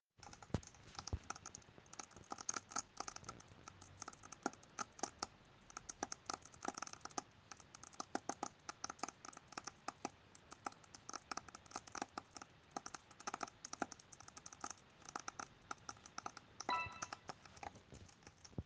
Mein Laptop macht komische Geräusche